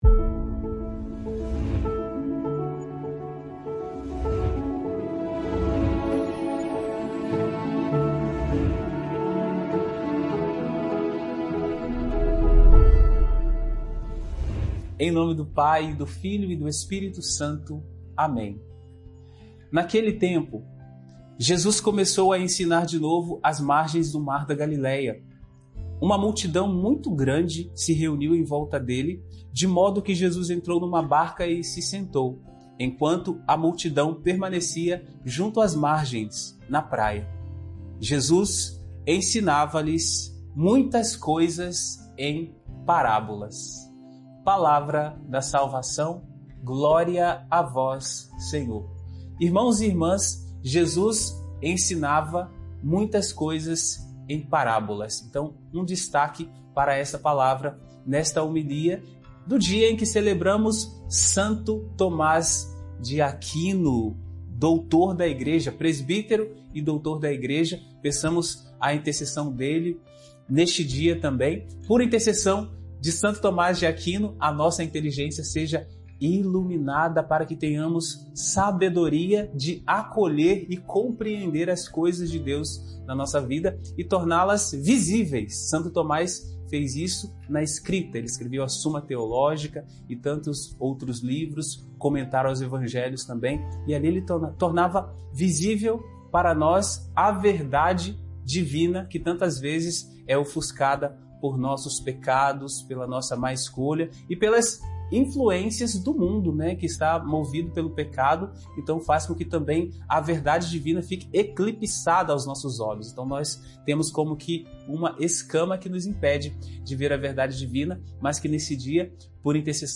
Evangelho de Marcos 4,1-20, homilia de hoje.